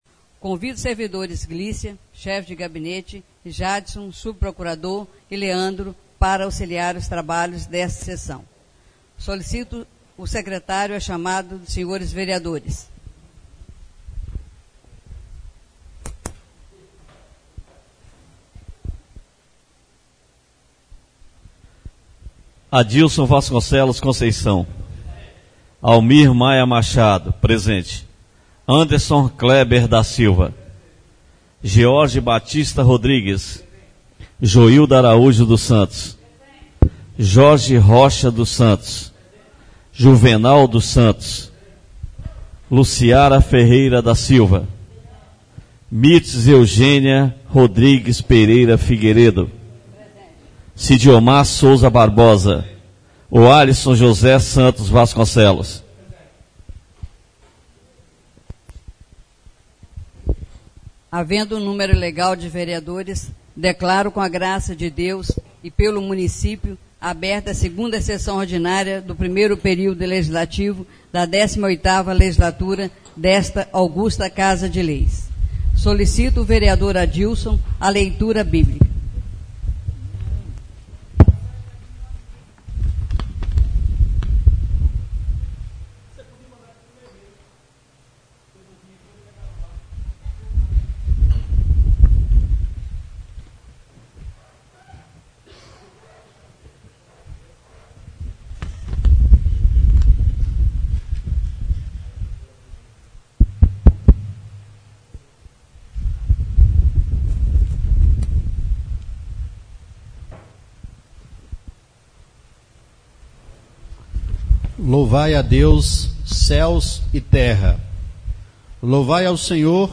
2ª (SEGUNDA) SESSÃO ORDINÁRIA DO DIA 09 DE MARÇO DE 2017-SEDE